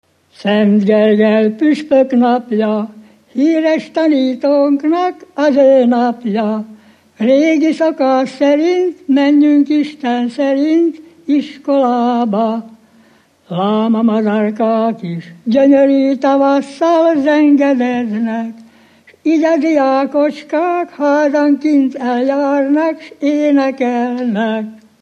Felföld - Bars vm. - Barslédec
ének
Műfaj: Gergelyjárás
Stílus: 8. Újszerű kisambitusú dallamok